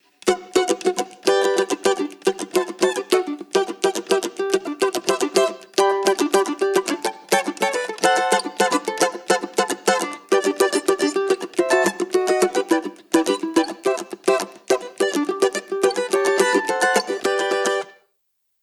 Très agréable, beau timbre équilibré.
cavac.mp3